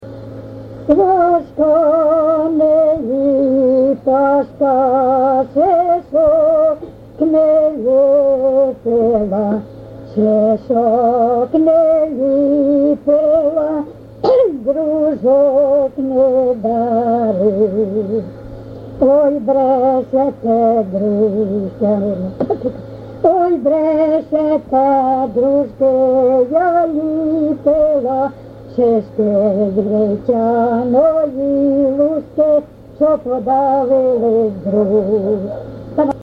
ЖанрВесільні
Місце записус. Коржі, Роменський район, Сумська обл., Україна, Слобожанщина